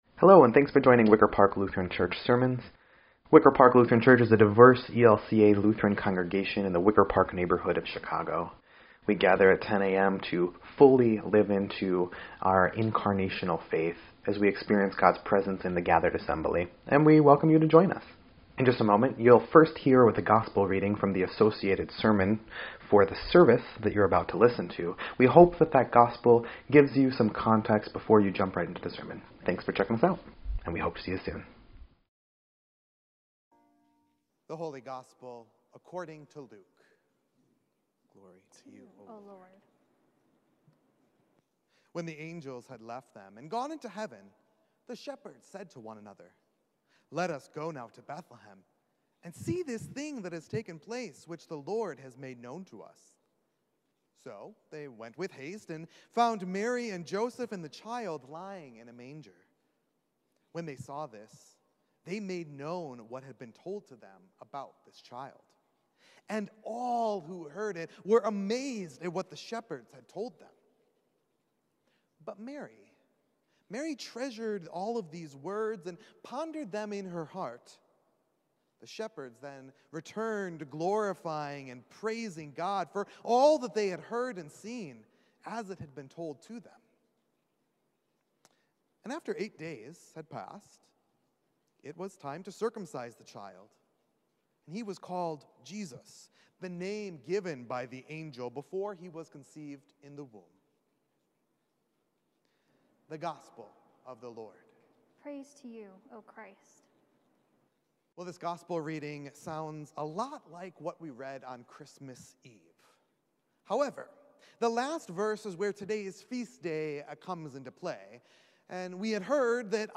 1.2.22-Sermon_EDIT.mp3